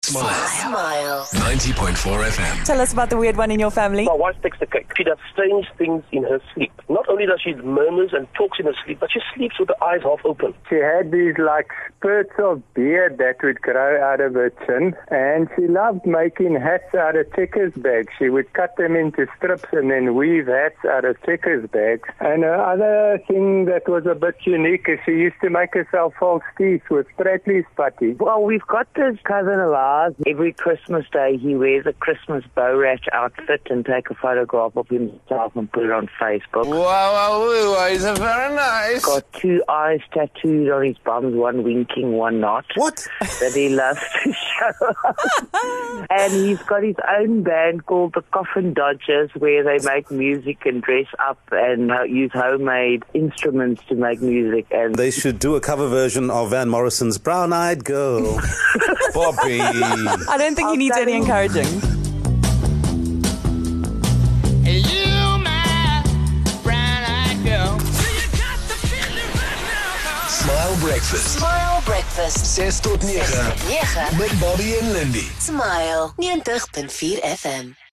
Some listeners phoned in to introduce us to the weird one in their family: